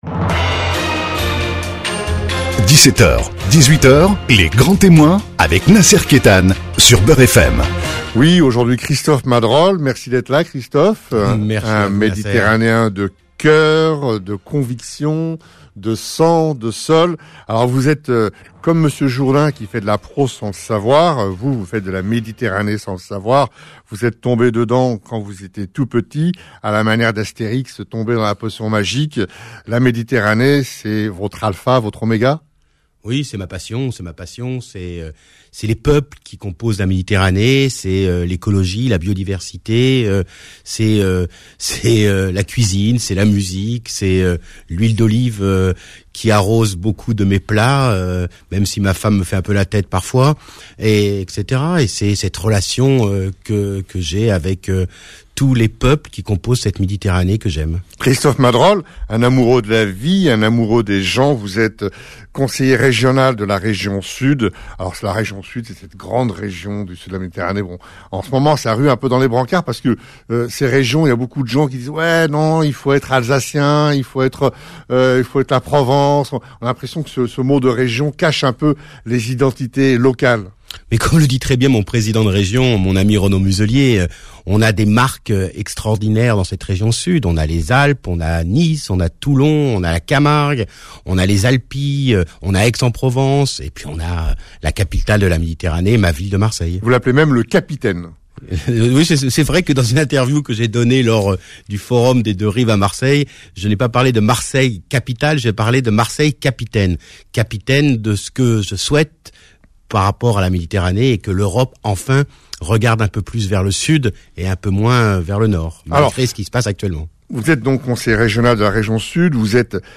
Face à face avec le grand témoin de l'actualité de la semaine !